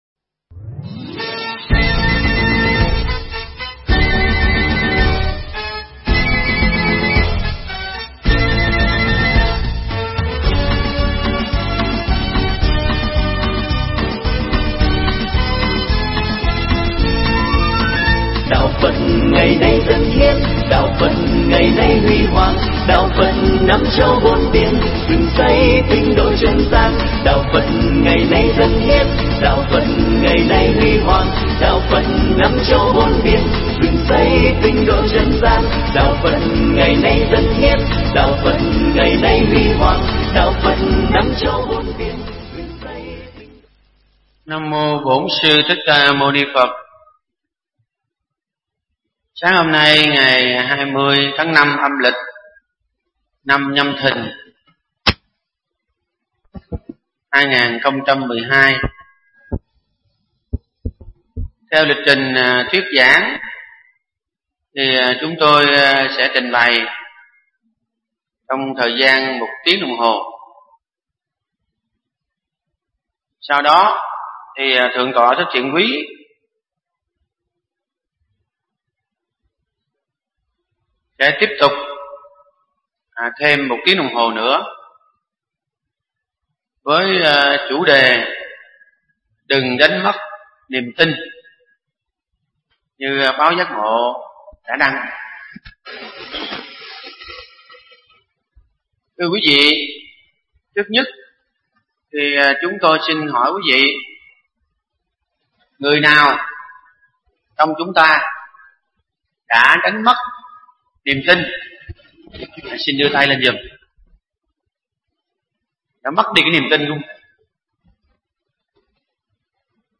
Mp3 pháp thoại Đừng Đánh Mất Niềm Tin